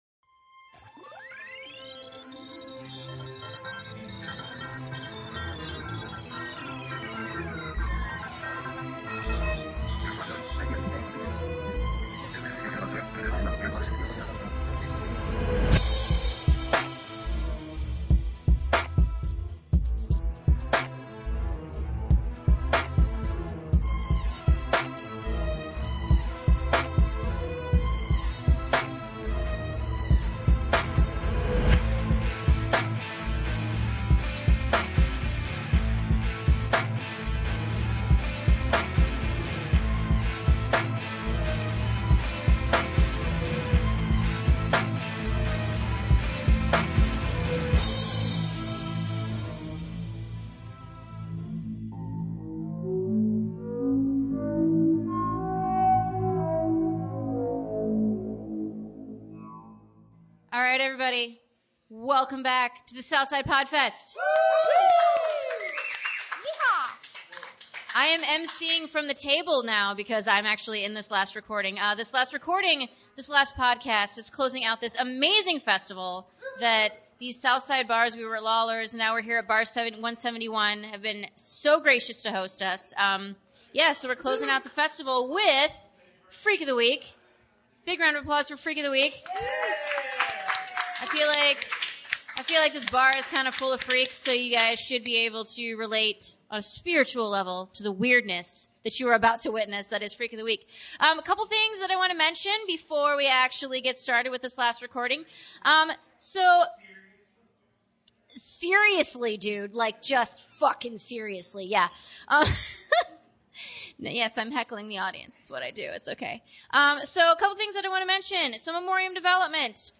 301 – CCSB Live From The South Side Podfest